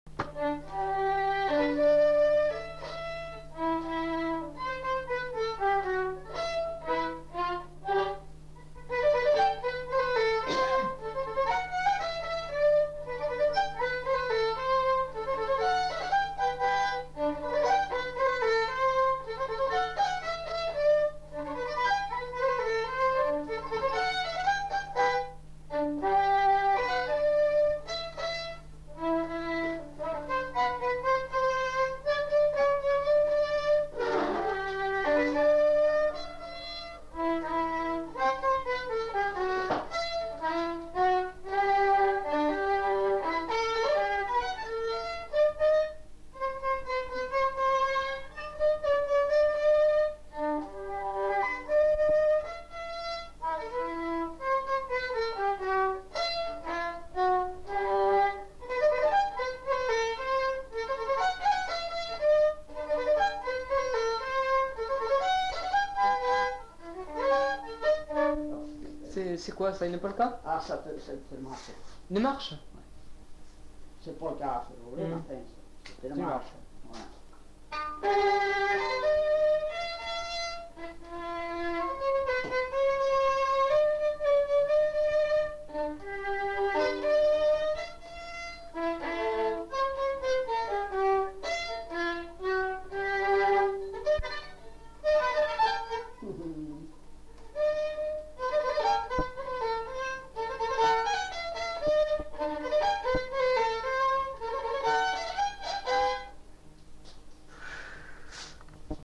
Lieu : Saint-Michel-de-Castelnau
Genre : morceau instrumental
Instrument de musique : violon
Danse : marche (danse)